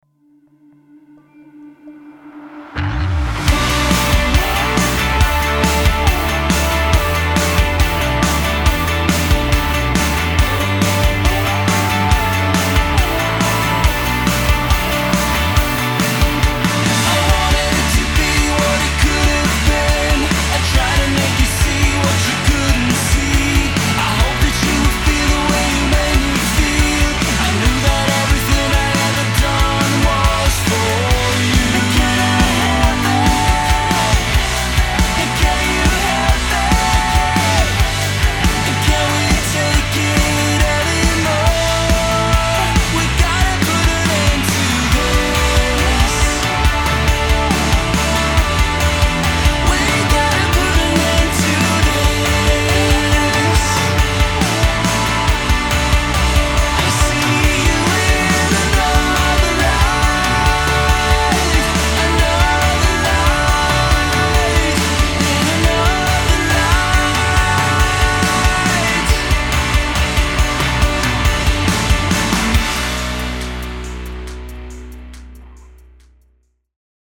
PORTFOLIO – DRUM RECORDINGS
ROCK